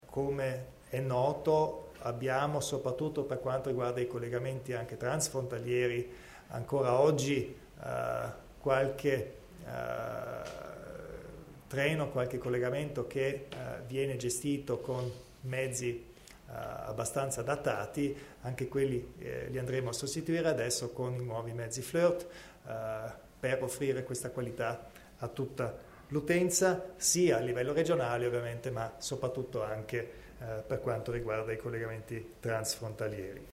Il Presidente Kompatscher illustra gli investimenti nel trasporto ferroviario